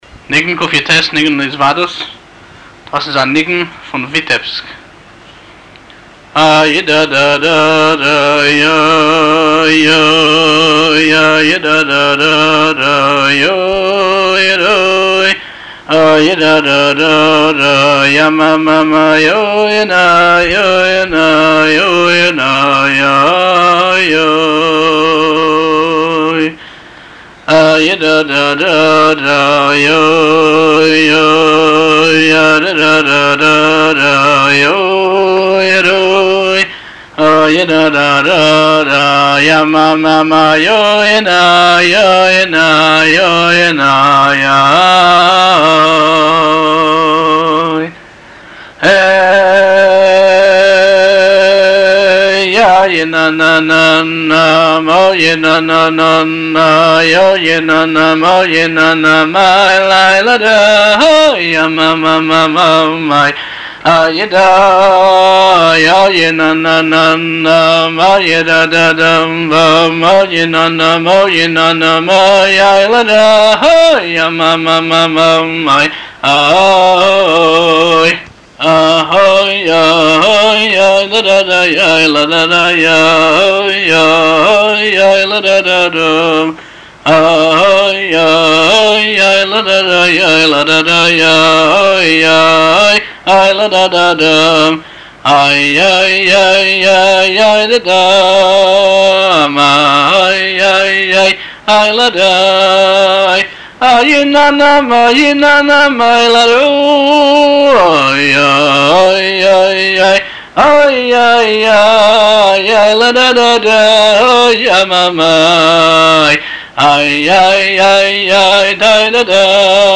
ניגון זה משתייך לניגוני התוועדות, אשר מרגלא בפי חסידי חב"ד לנגנם בהתוועדויות חסידיות ובכל עת שירה. הקו האופייני של ניגונים אלו הוא; רגשיות השירה, דבקות הנפש והתעוררות הלב לתשובה ועליה.